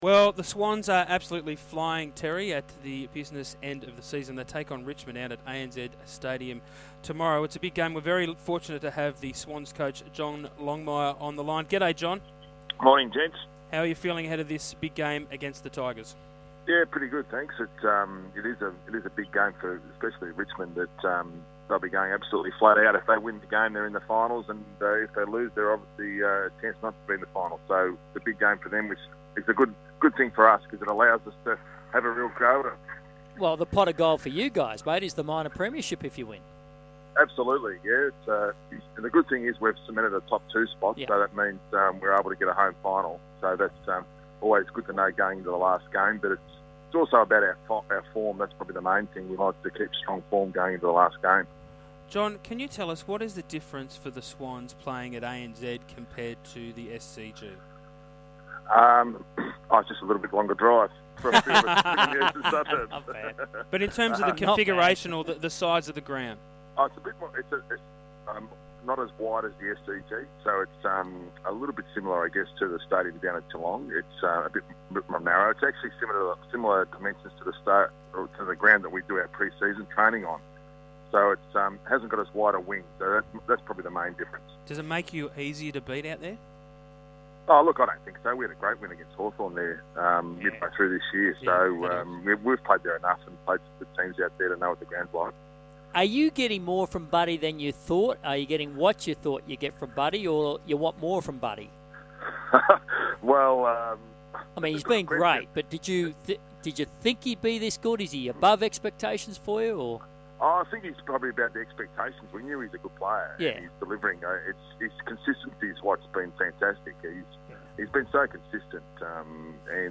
Sydney Swans coach John Longmire appeared on Sky Radio's Big Sports Breakfast program on Friday August 29, 2014